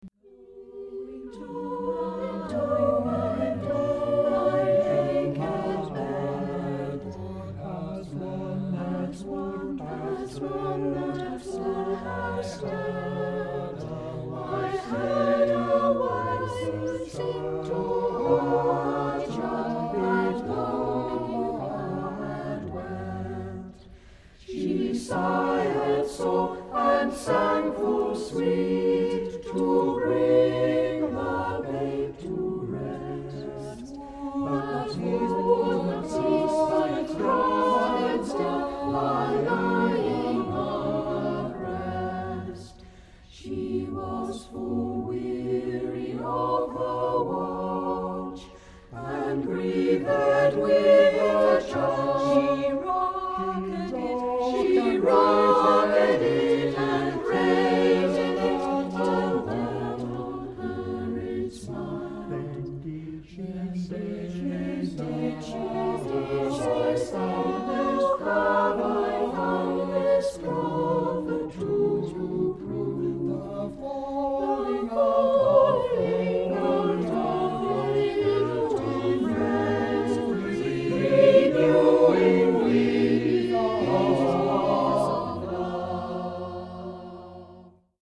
Musyck Anon, a cappella choir in Evesham, Worcestershire and Gloucestershire